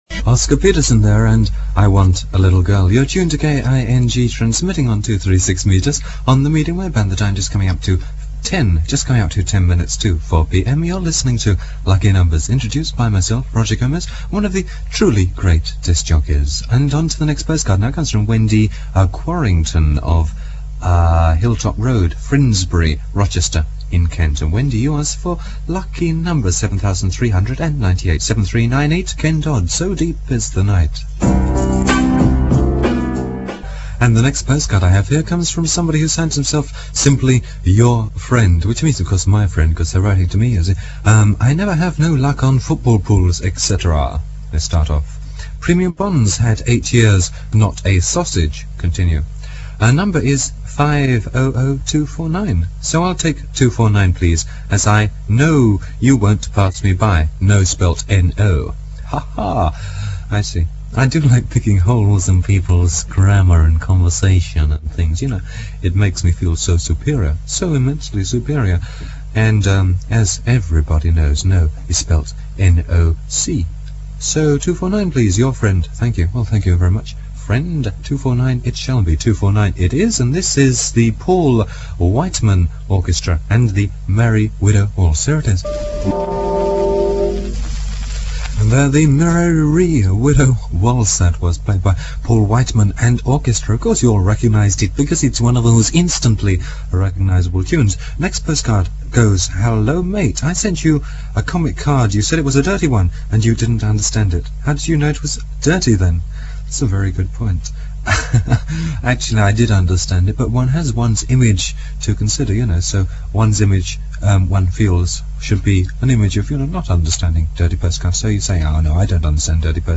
For the first time you can hear King Radio as it sounded in the studio.